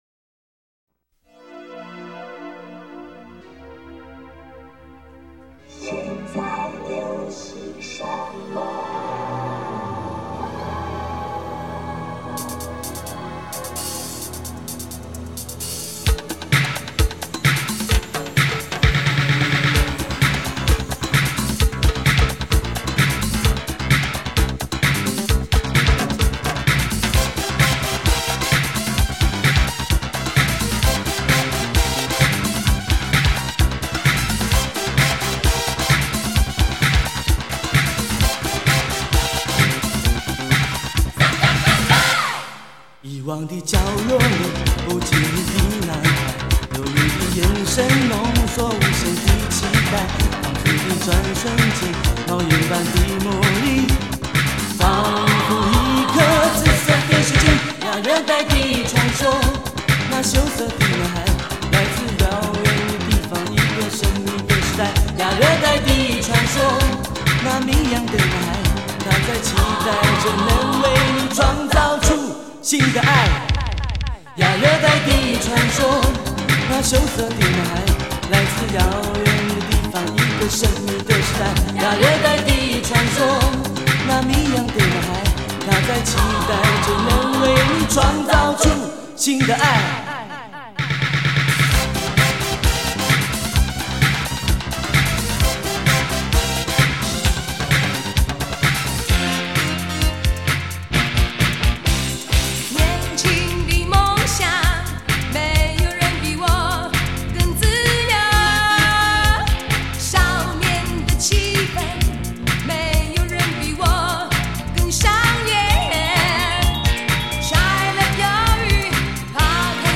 最多的流行的歌曲